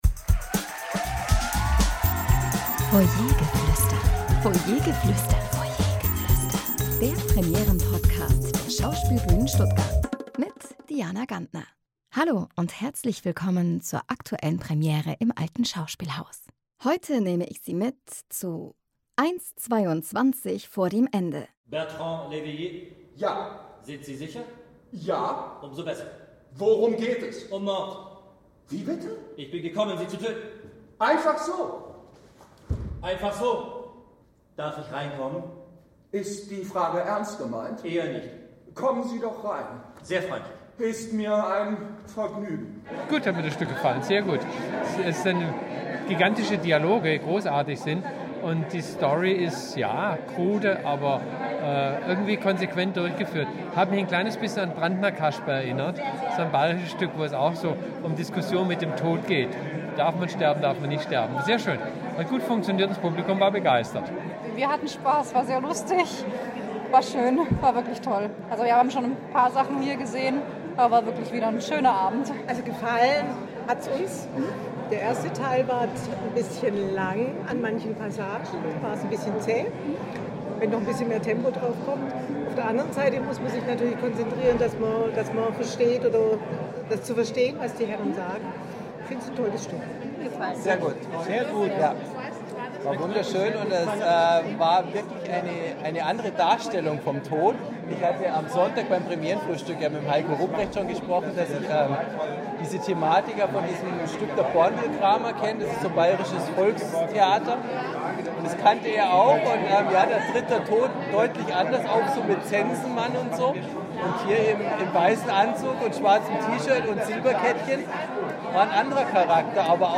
Publikumsstimmen zur Premiere von “1h22 vor dem Ende”